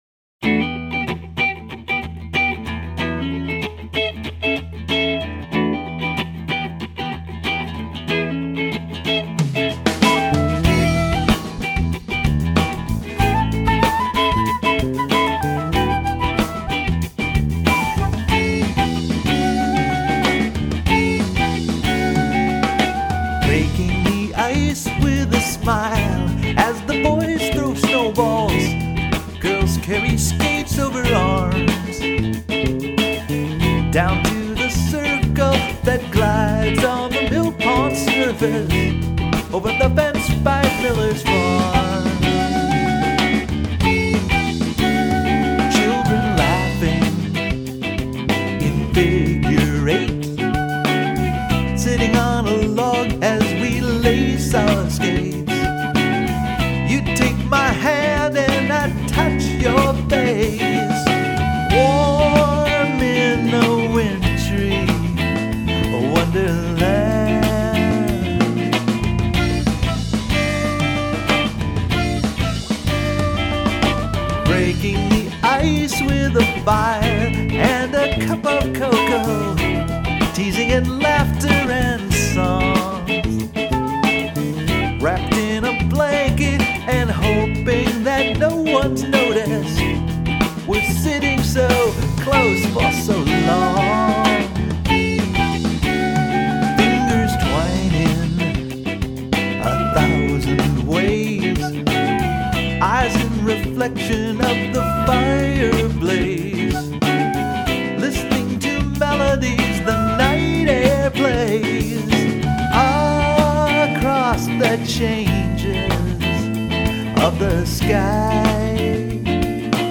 vocal harmonies
flute and accordion accompaniment. We had such a good time that after the show, we made a quick recording of the tunes at my studio.